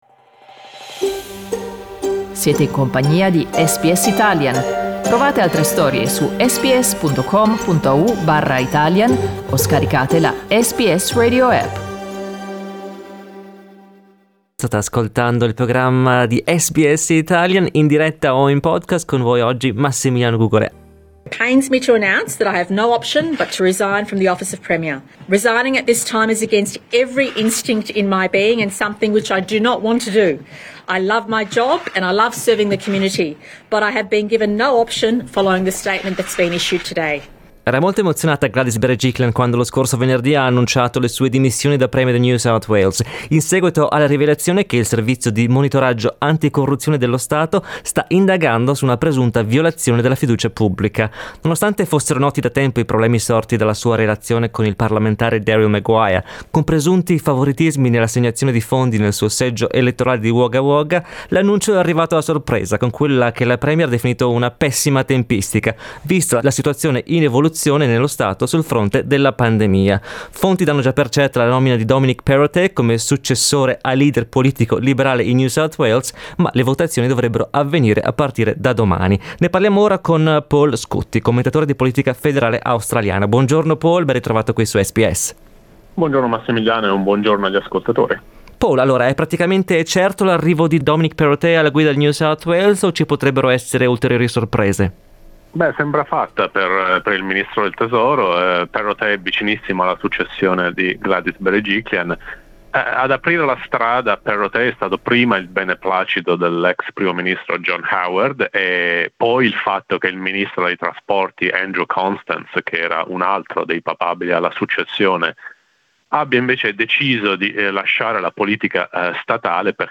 commentatore di politica federale